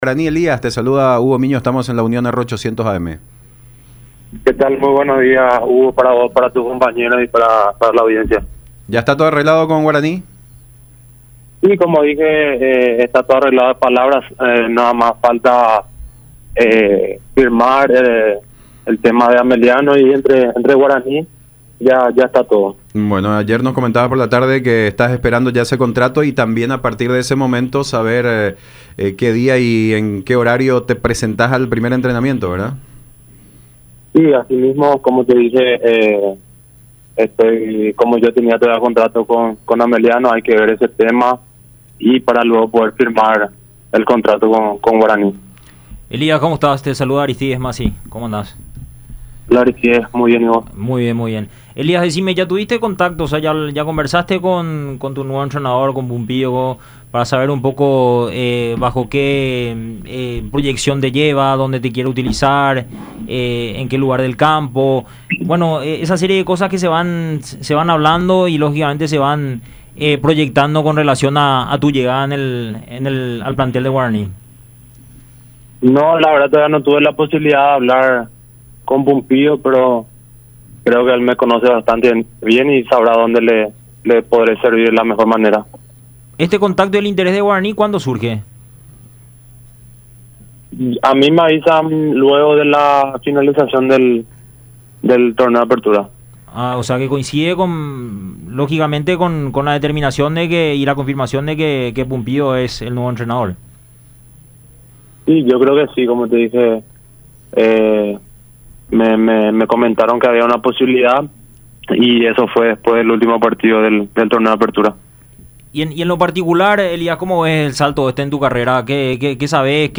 “Sé lo que significa Guaraní y a todo lo que aspira, está obligado siempre a pelear los campeonatos. Espero estar a la altura de las exigencias”, resaltó en charla con Fútbol Club por radio la Unión y Unión TV.